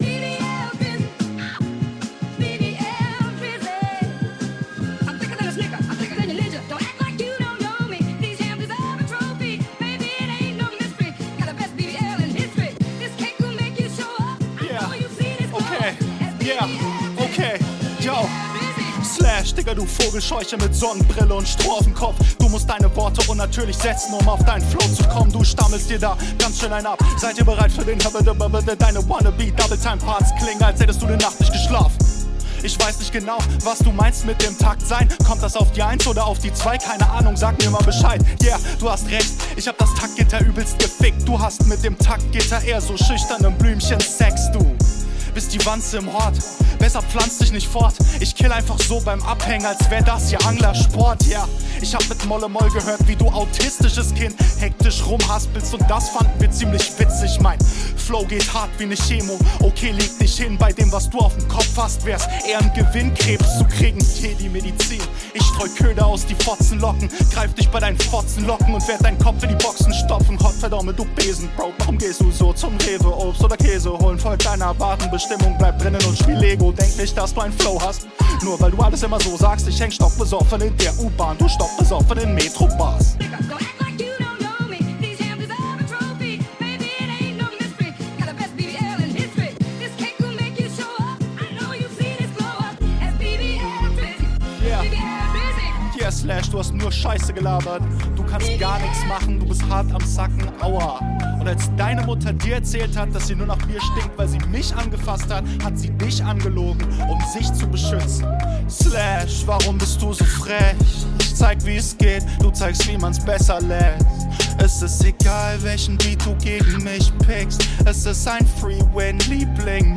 Flow kommt auch gut, aber im Vergleich ein wenig schlechter.